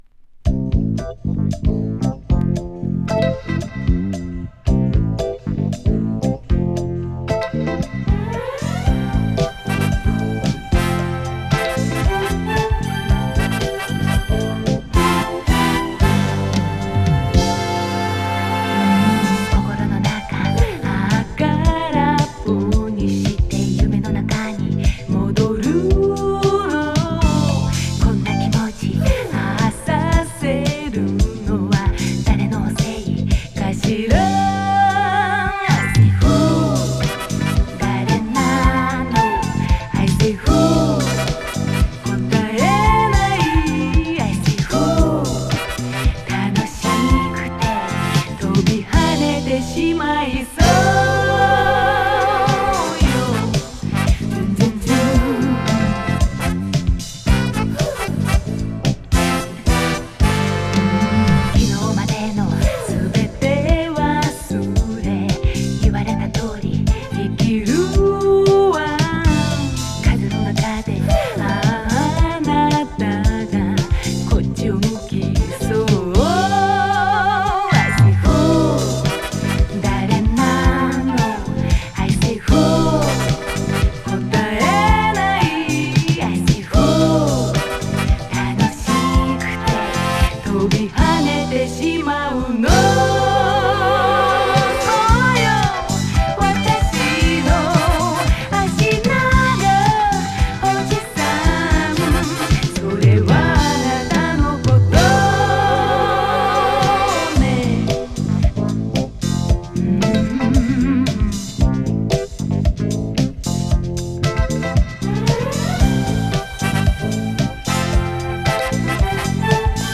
和物Light Mellow/AOR〜ジャパニーズ・シティ・ポップ名盤。